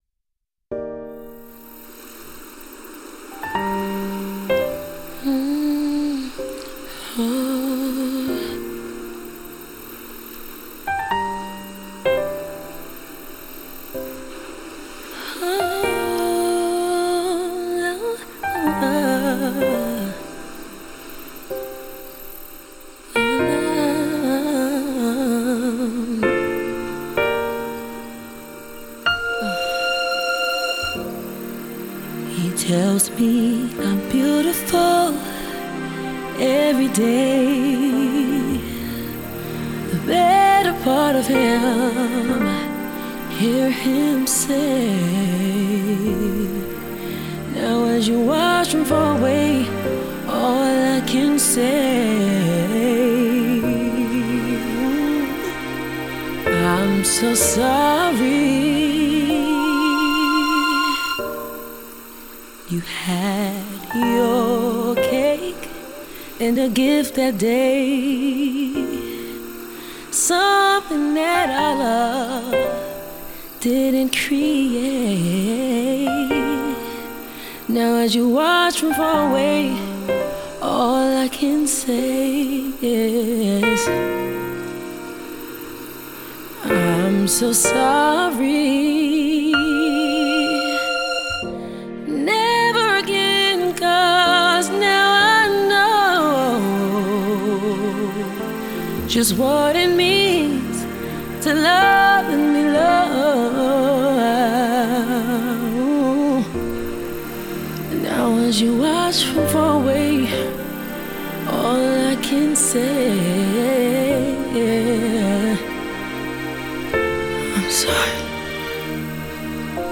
apologetic ballad
This remorseful song